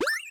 Bounce4.wav